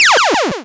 fall4.wav